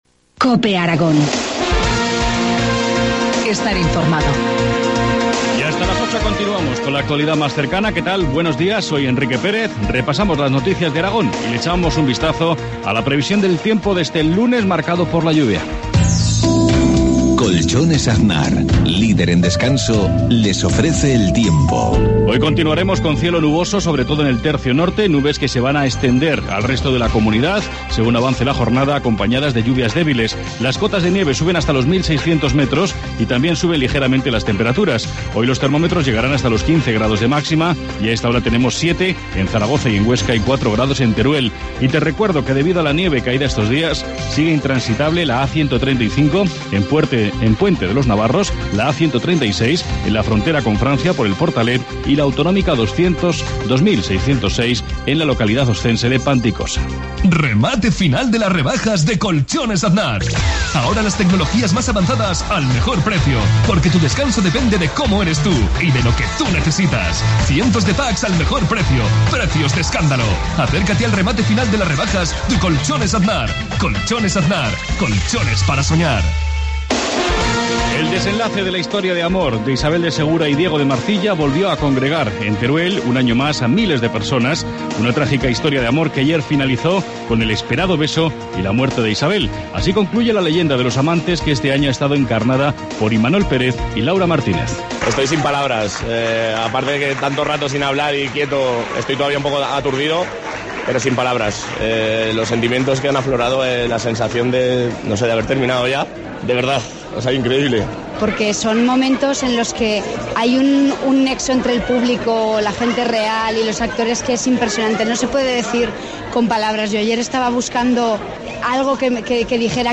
Informativo matinal, lunes 18 de febrero, 7.53 horas